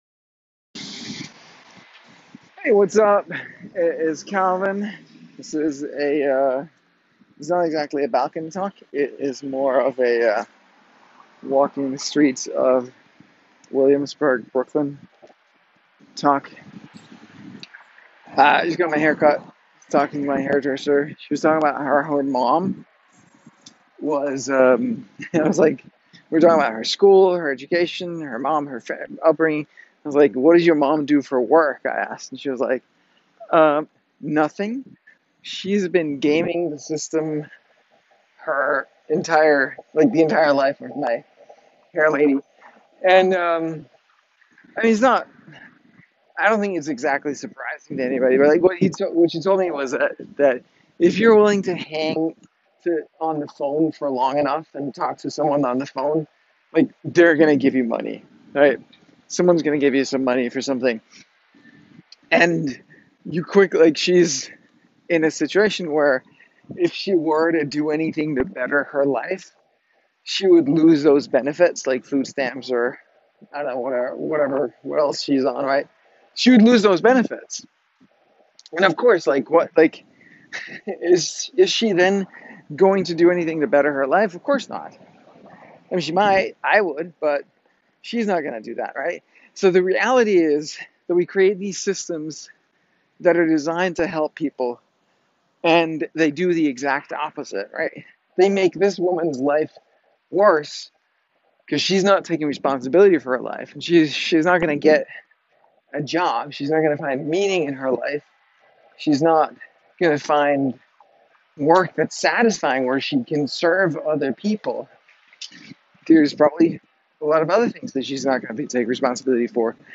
Nothing meaningful in life happens without taking full responsibility and choosing to change. In this quick street-walk talk, I share thoughts on victim mindsets, gaming the system, personal growth, and why it’s on you to create the life you want.